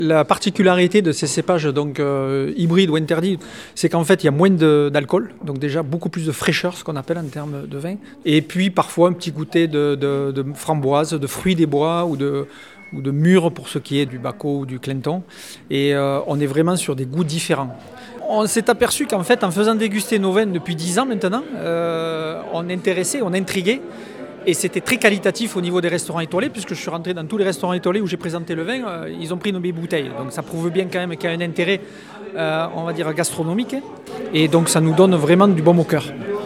Des vins aux goûts nouveaux, encore inconnus de nos papilles, produits notamment par David Flayol, maire de Molezon. Le vigneron présente son produit.